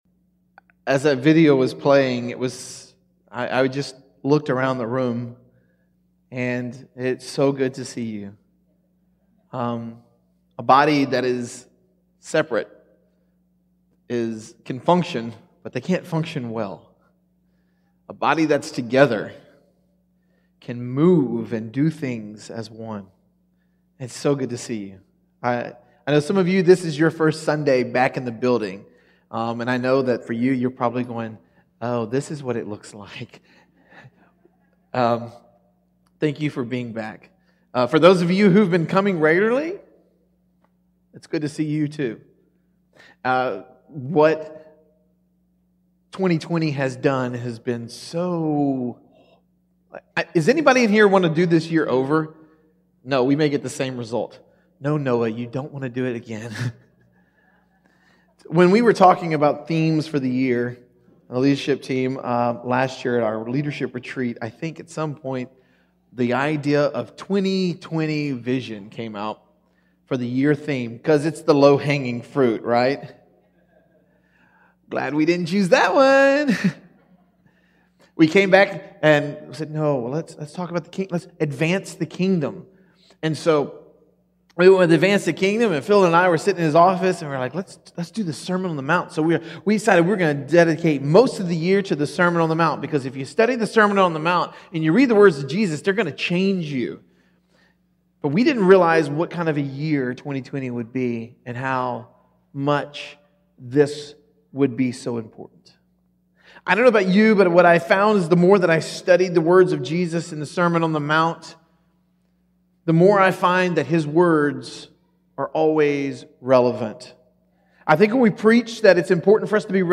The conclusion to our sermon series on the Sermon on the Mount.